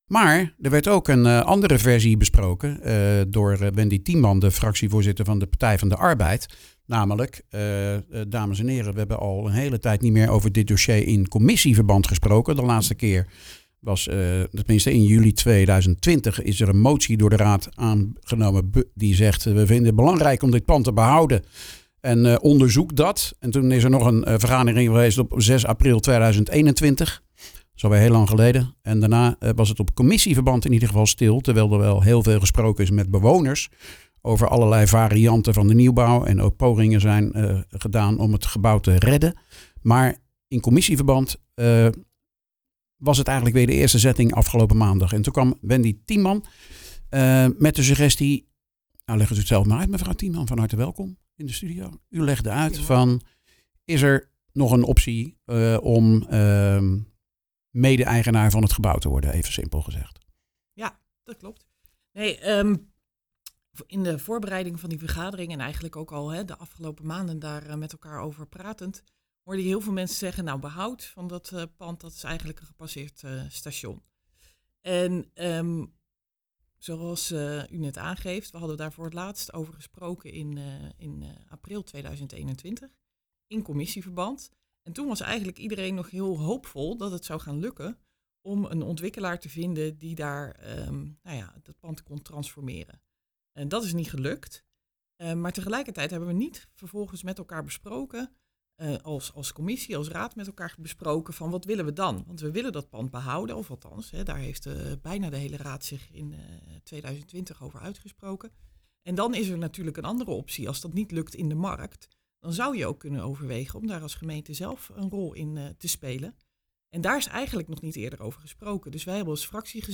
praat erover met de raadsleden Wendy Tieman van de PvdA en Gerrit Spruit van Leefbaar Capelle.